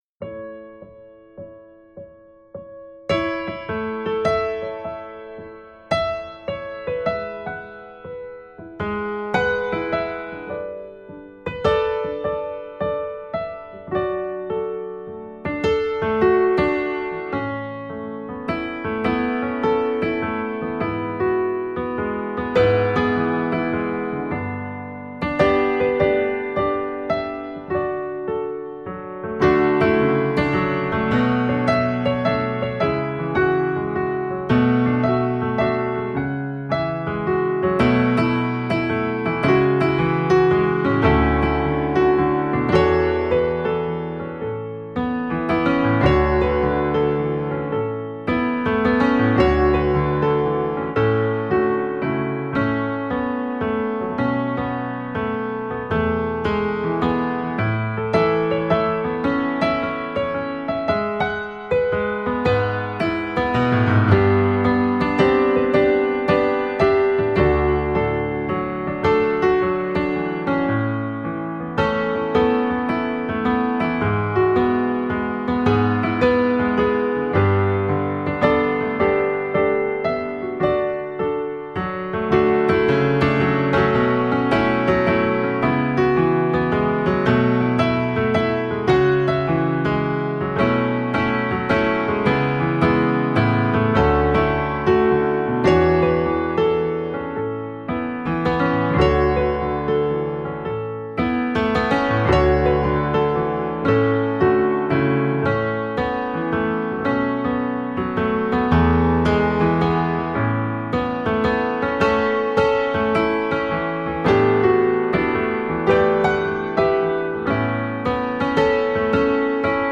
Genre: New Age, Instrumental, Piano.